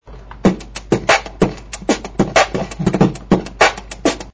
подскажите что за снэр на записи и как его делать